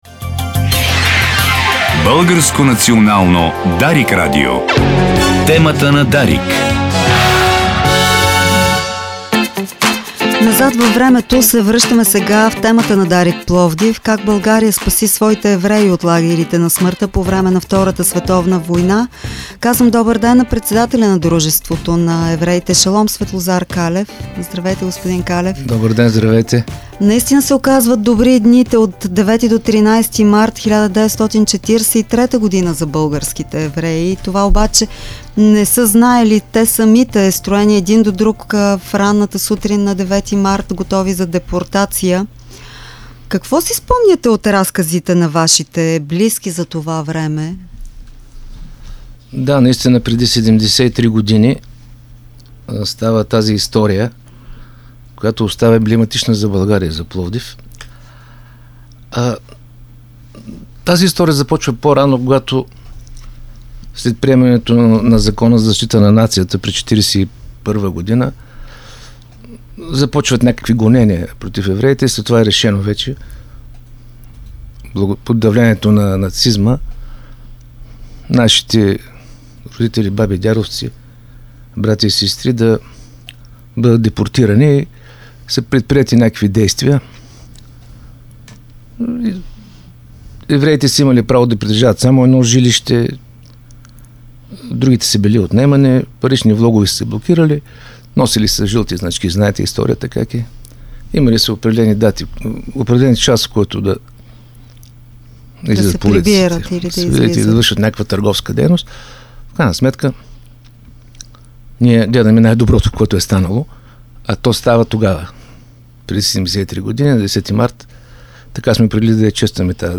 в студиото на Дарик